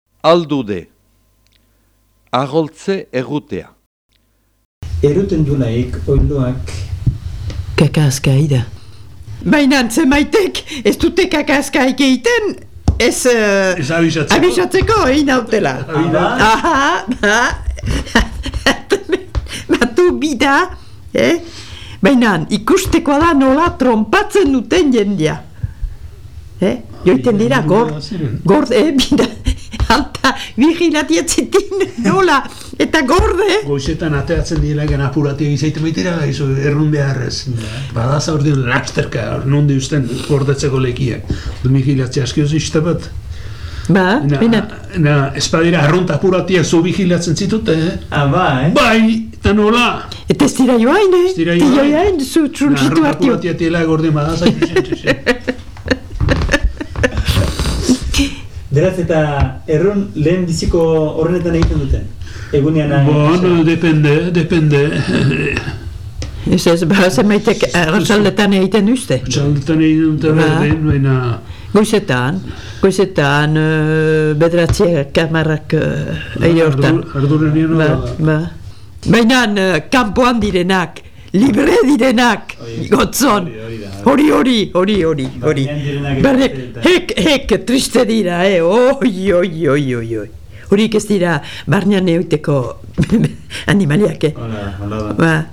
6.1. ALDUDE
Bi lekukoek kontatzen dute nolako joera duten oilo zenbaitek jendeen ixilean erruteko.